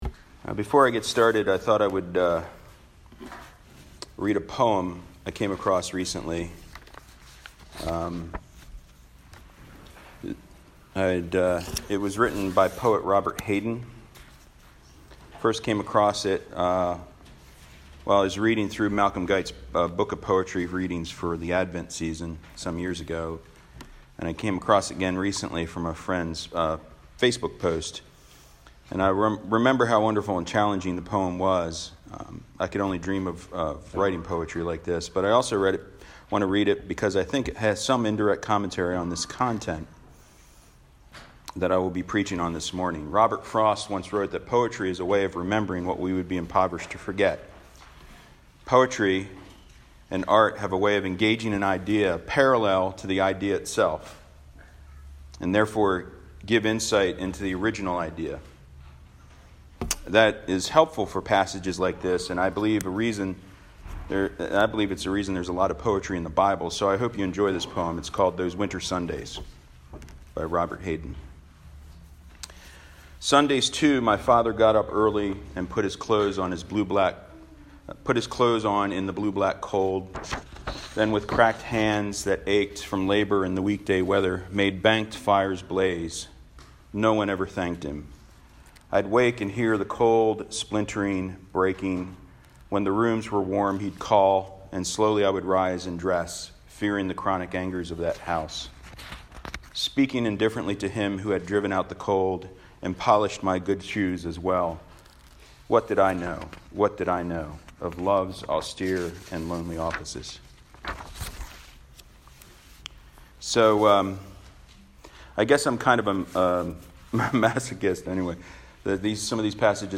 Sermon for Hill City Church sabbath service on Sunday March 1, 2020.